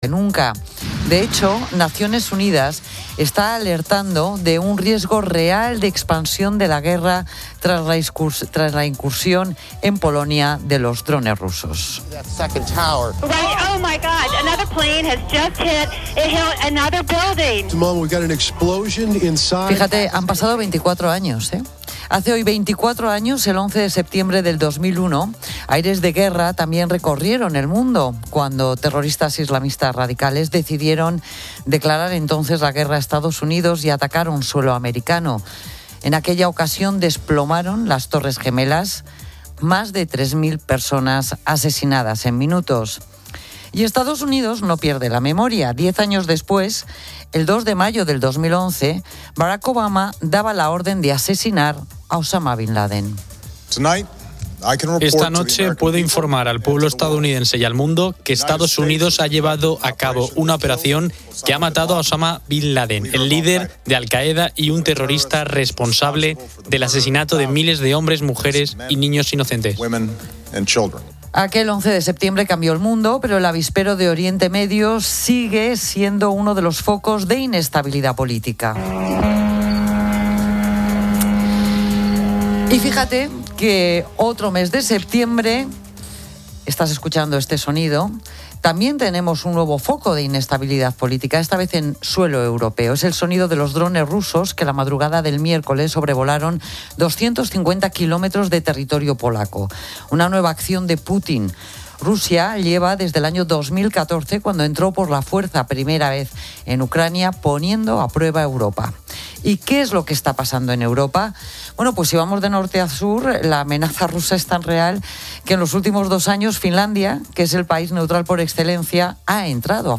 Se entrevista a alumnos y profesores que destacan sus ventajas.